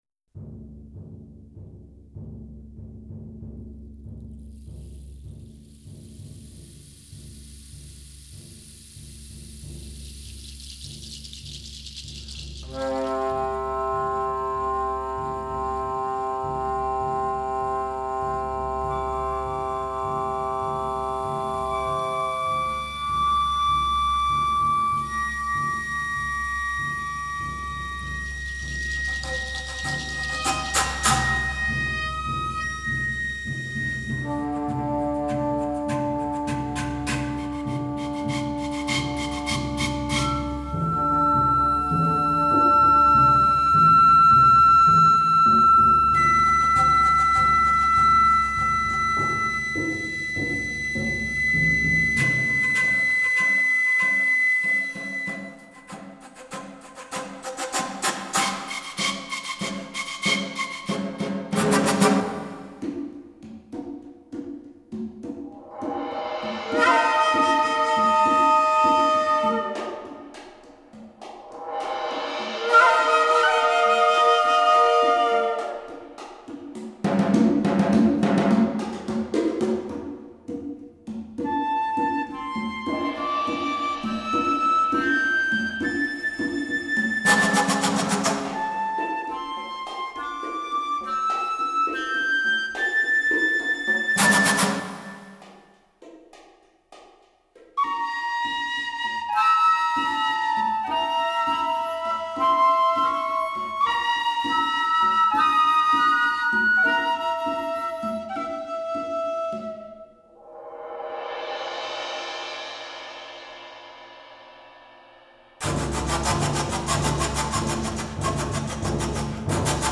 Antara para zampoñas, tarkas, flautas y percusiones